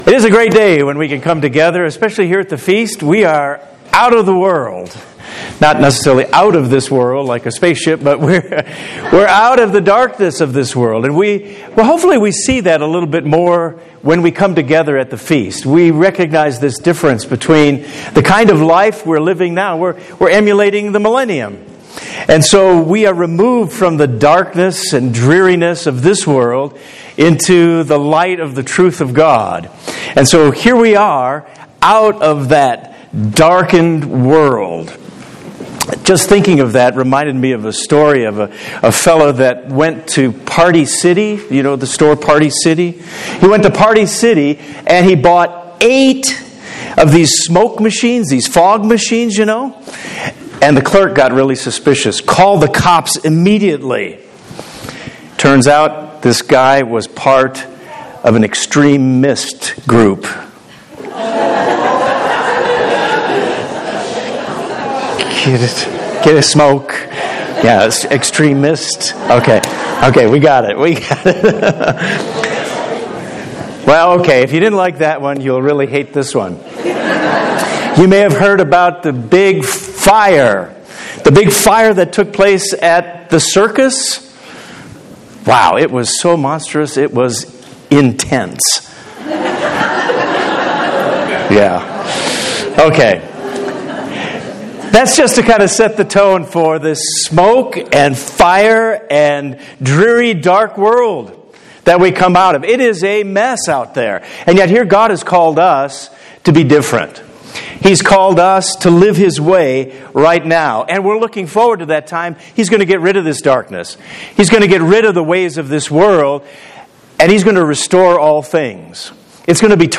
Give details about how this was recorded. This sermon was given at the Lake Junaluska, North Carolina 2019 Feast site.